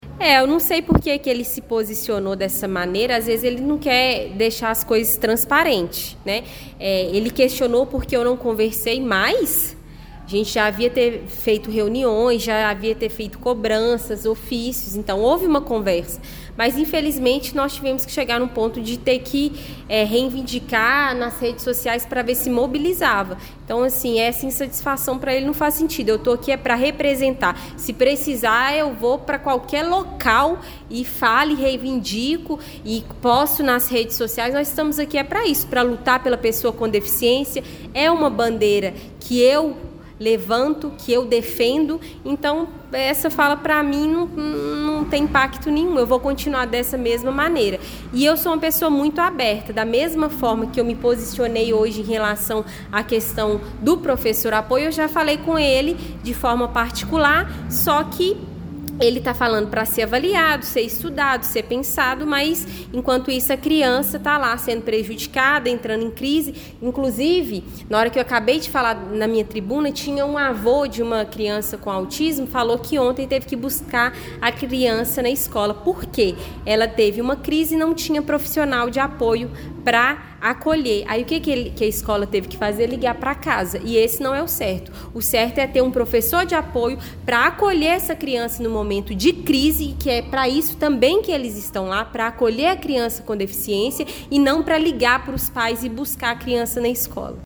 Os vereadores participaram da 6ª Reunião Ordinária da Câmara Municipal de Pará de Minas, realizada ontem (24), com pautas voltadas a direitos sociais e à inclusão.